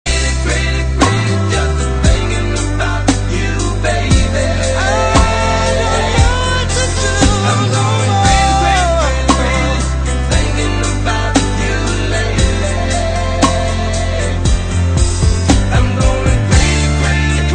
RnB & Garage